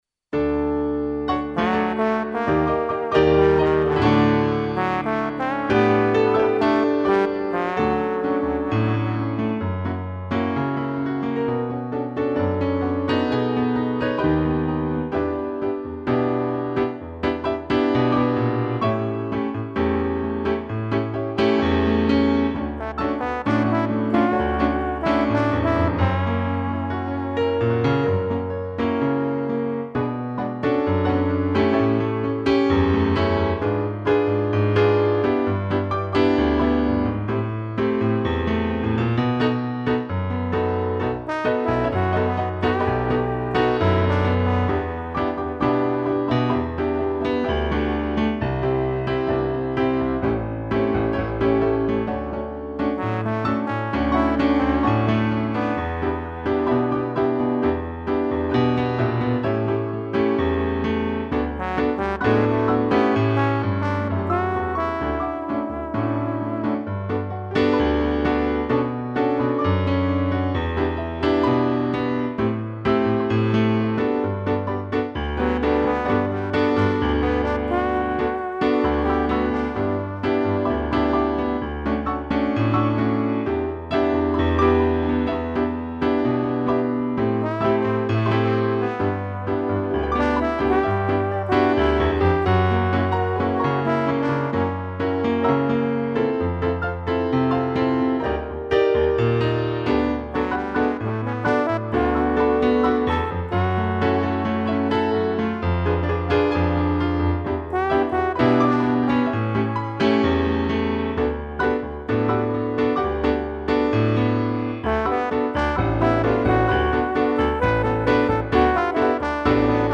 2 pianos e trombone
(instrumental)